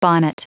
Prononciation du mot bonnet en anglais (fichier audio)
Prononciation du mot : bonnet